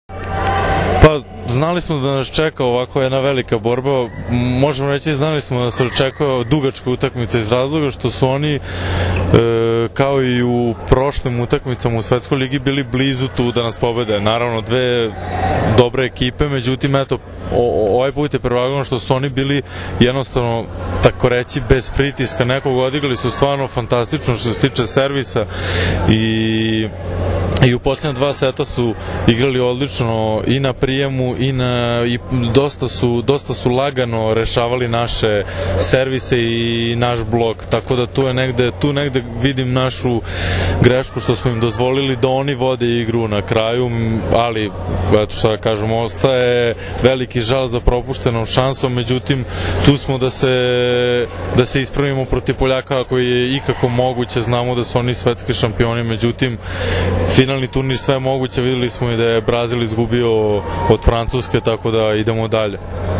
IZJAVA NIKOLE JOVOVIĆA